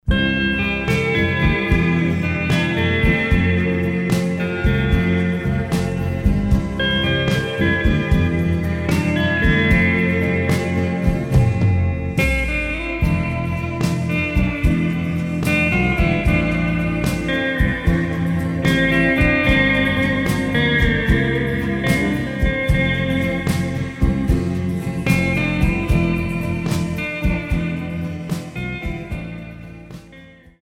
Genre : Rock’ n’ Roll, Instrumental